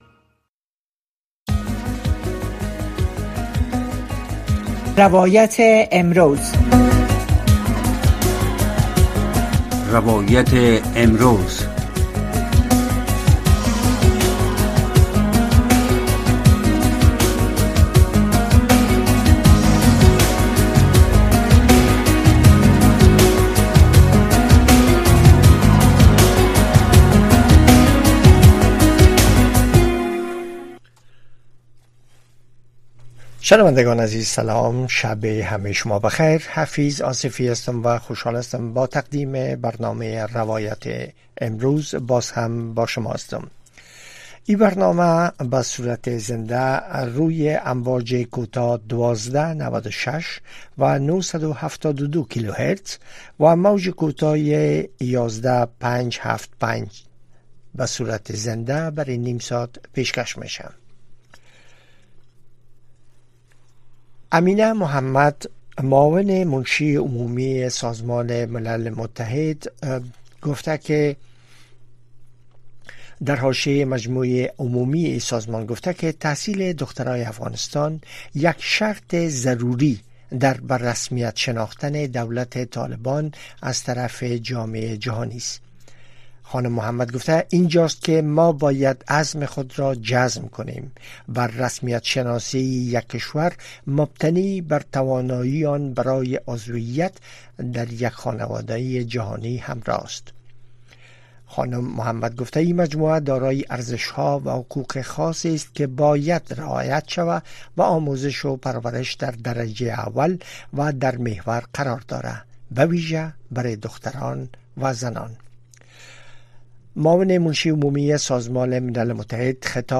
در برنامۀ روایت امروز شرح وضعیت در افغانستان را از زبان شهروندان و شرکت کنندگان این برنامه می‌شنوید. این برنامه هر شب از ساعت ٩:۳۰ تا ۱۰:۰۰ شب به گونۀ زنده صدای شما را پخش می‌کند.